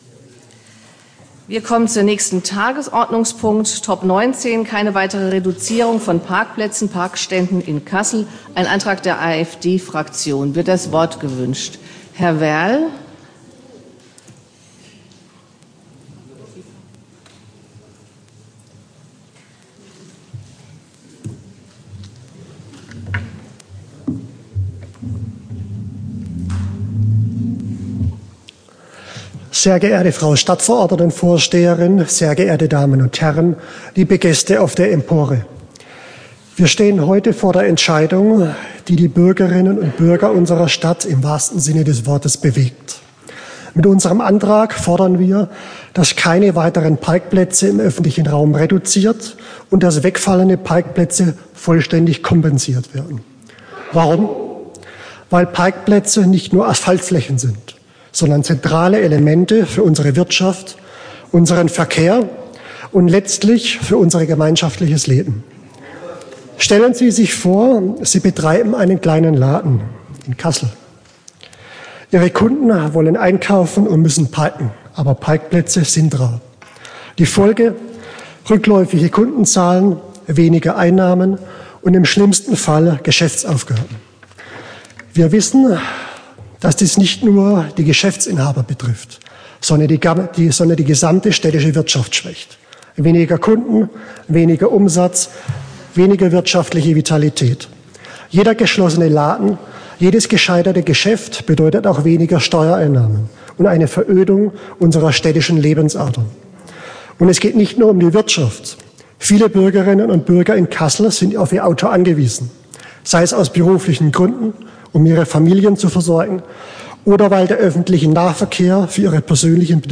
Tonaufzeichnung Tagesordnungspunkt 19 (exportiert: 27.11.2024)